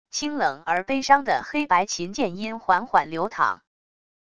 清冷而悲伤的黑白琴键音缓缓流淌wav音频